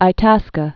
(ī-tăskə)